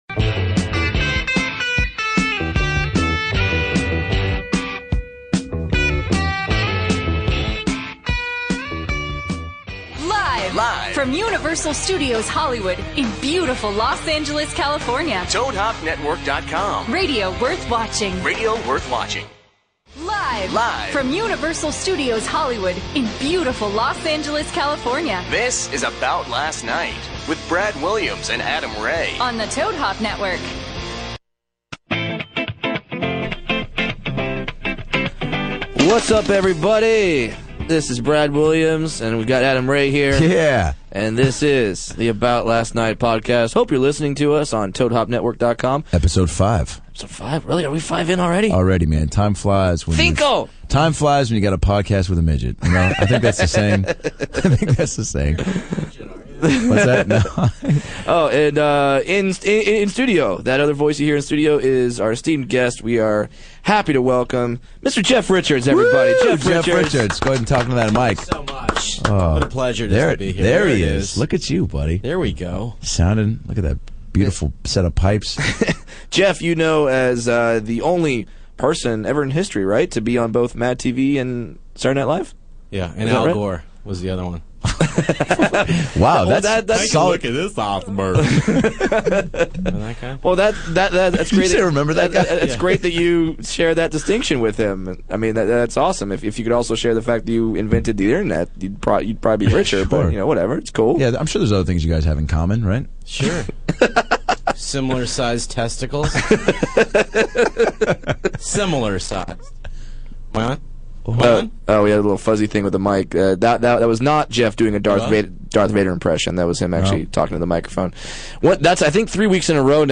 funny man and impressionist